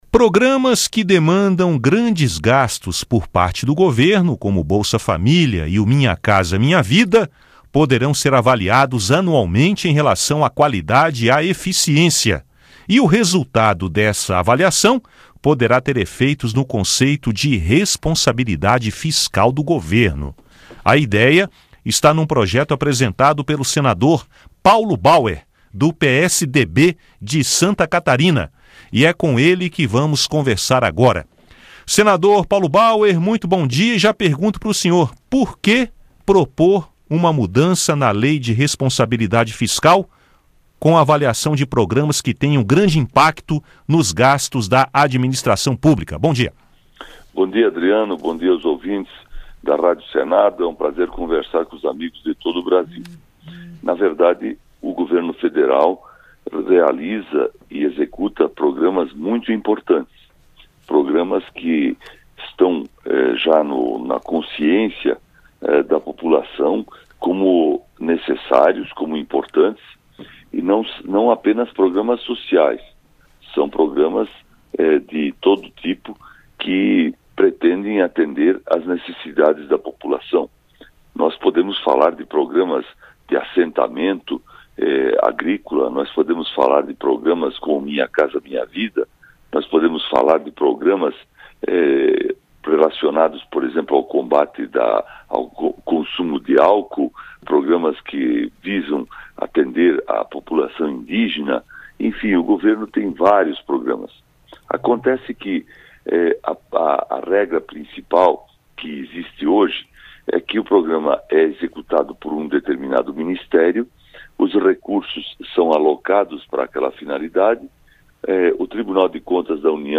Ele concedeu entrevista